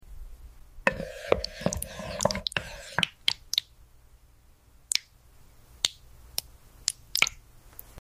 I love the sound of these colorful little pearls. It's the perfect way to unwind after a long day.